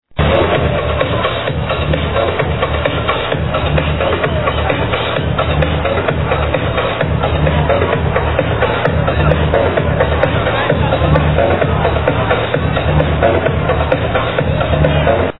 massive/electronic tracks
sorry for the bad quality of sound...
the sample quality is too bad..